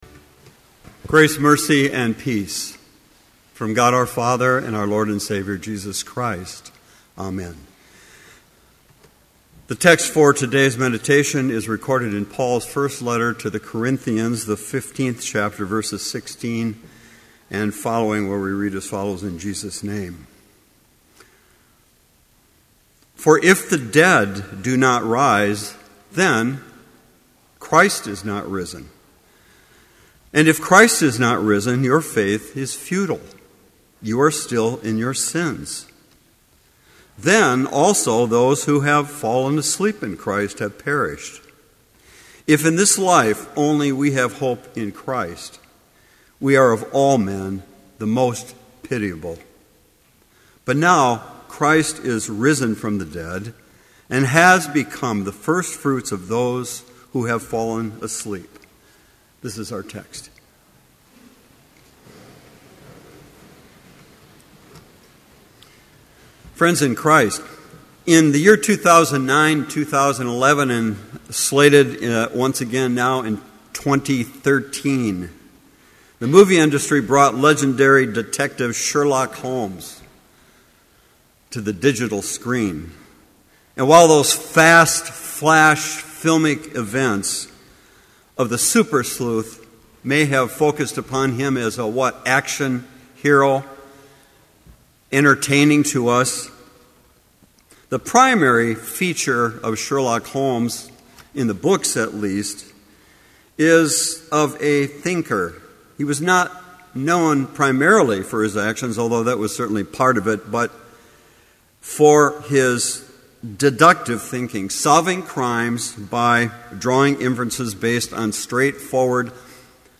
Complete service audio for Chapel - April 19, 2012